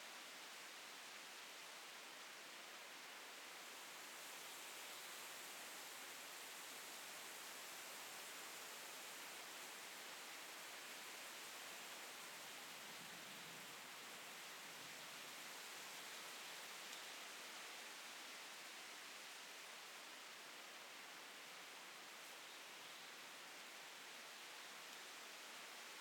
leaves.ogg